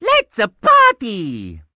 Mario saying "Let's-a party" before starting a board. From Mario Party 4.